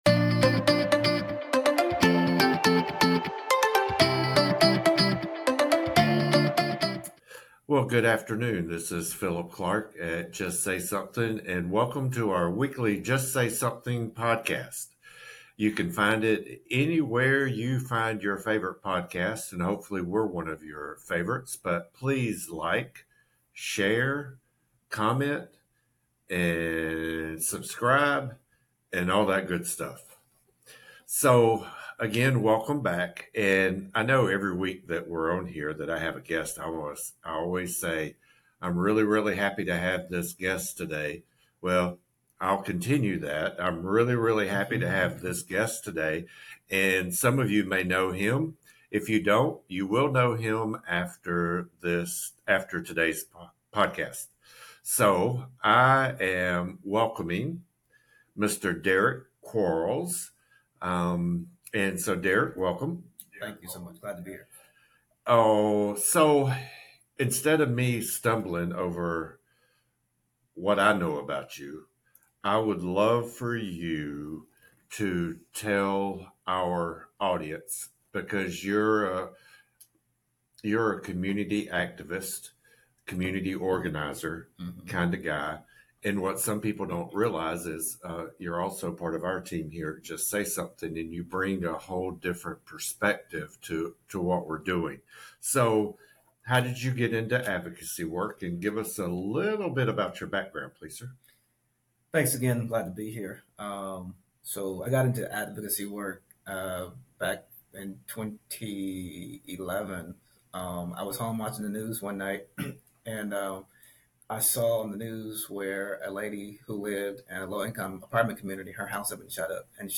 The conversation also touches on the importance of youth empowerment, leadership development, and the upcoming Take It Back Youth Summit.